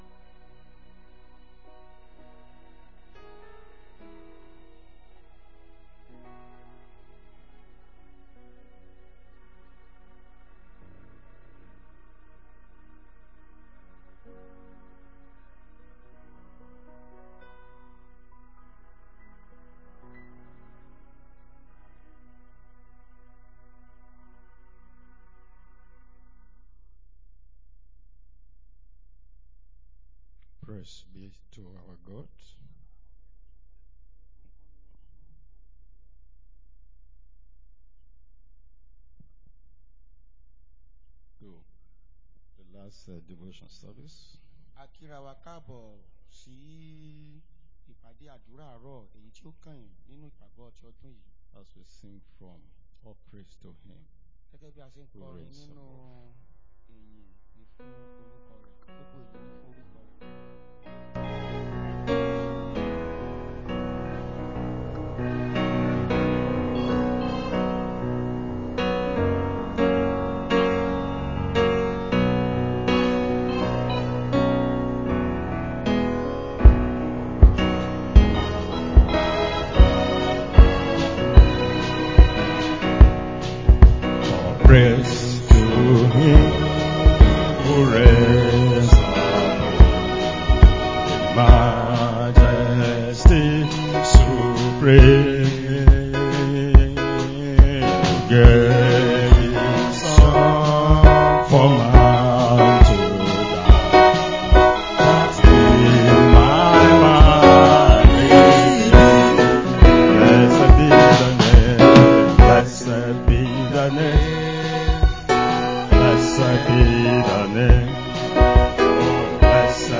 Closing Service